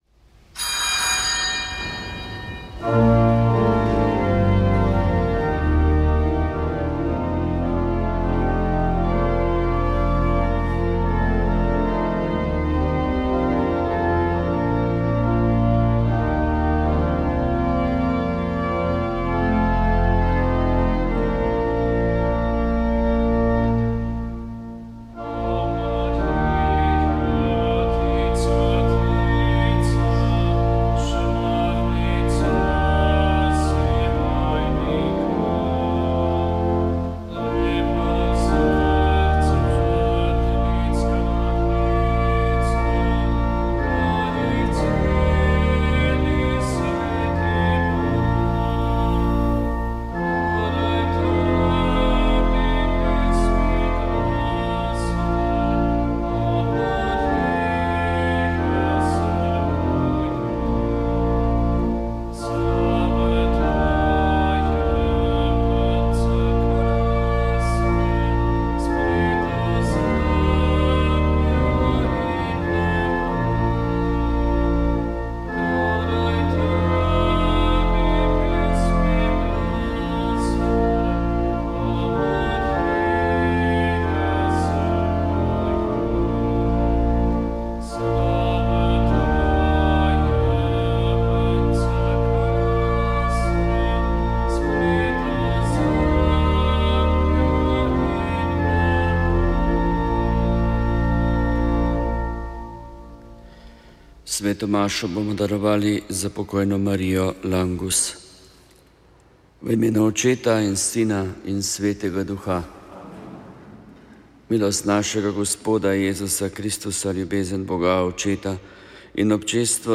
Sv. maša iz stolne cerkve sv. Janeza Krstnika v Mariboru 11. 9.